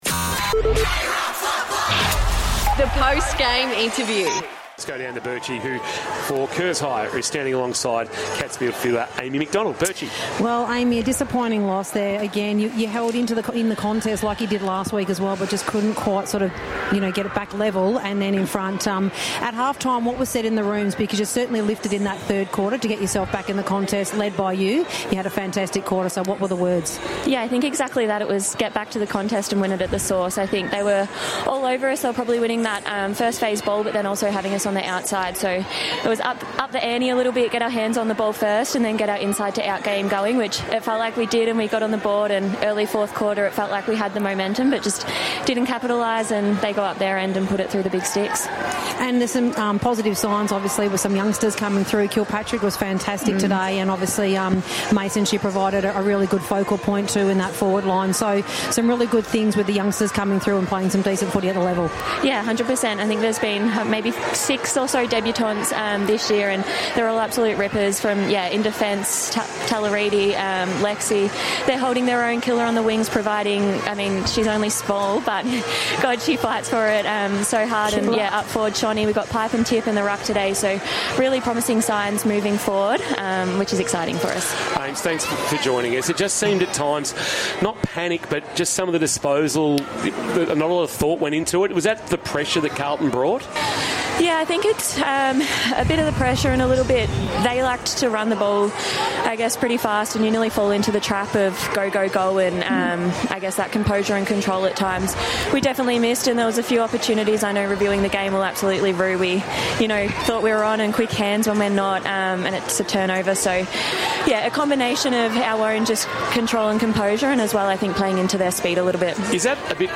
2025 - AFLW - Round 8 - Geelong vs. Carlton - Post-match interview